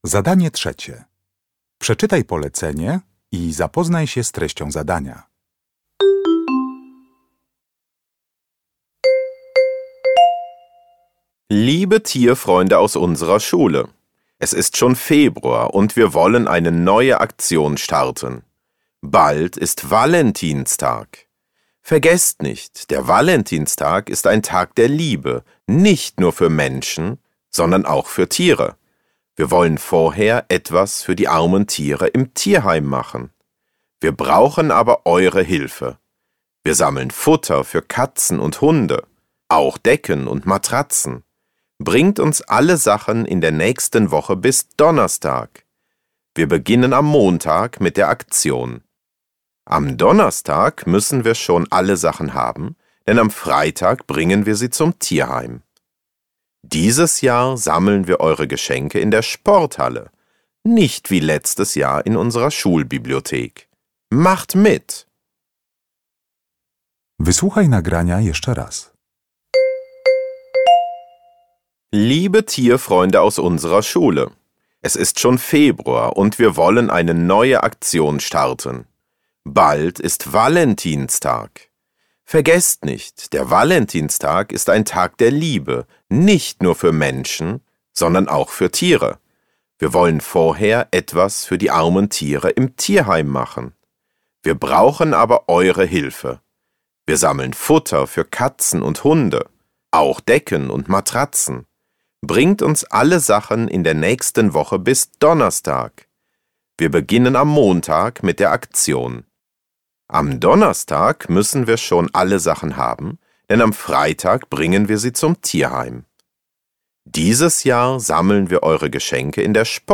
Uruchamiając odtwarzacz z oryginalnym nagraniem CKE usłyszysz dwukrotnie komunikat na temat akcji szkolnej.